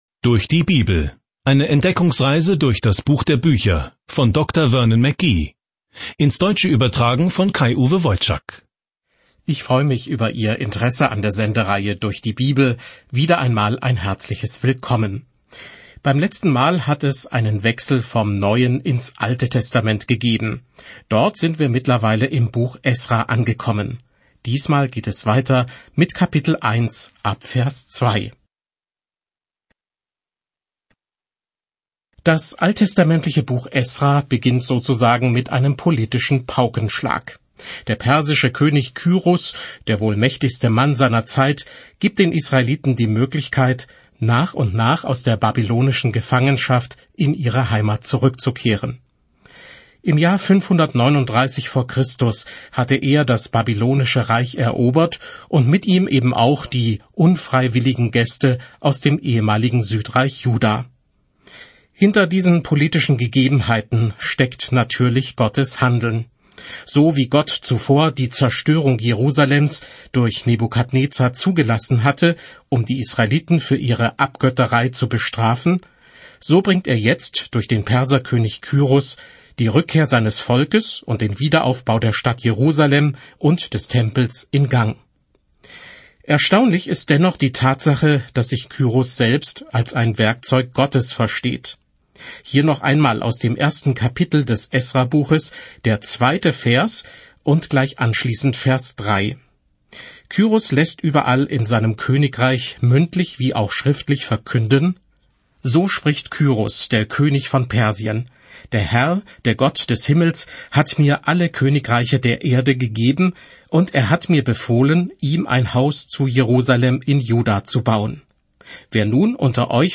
Die Heilige Schrift Esra 1:2-11 Esra 2 Tag 1 Diesen Leseplan beginnen Tag 3 Über diesen Leseplan Das aus der Gefangenschaft zurückgekehrte Volk Israel baut den Tempel in Jerusalem wieder auf, und ein Schriftgelehrter namens Esra lehrt es, wie man Gottes Gesetzen wieder gehorcht. Reisen Sie täglich durch Esra, während Sie sich die Audiostudie anhören und ausgewählte Verse aus Gottes Wort lesen.